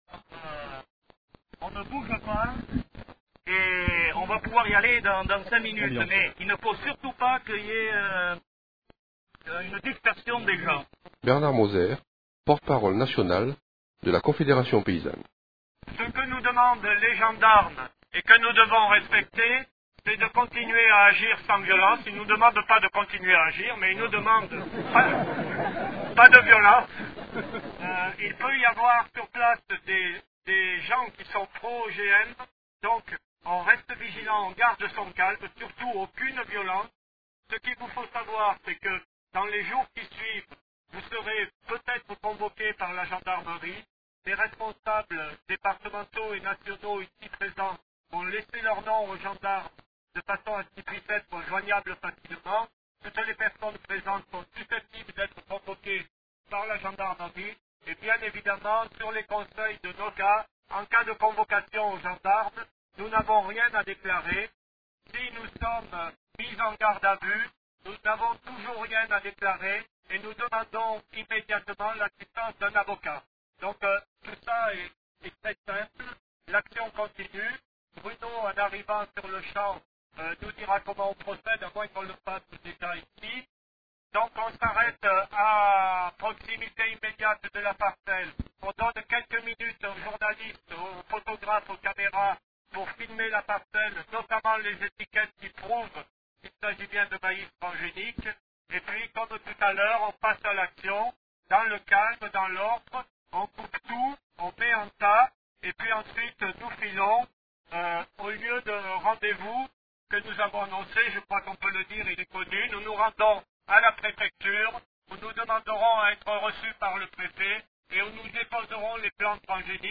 Les Interviews de Radio-Méga
le 26 Août 2001 à Salette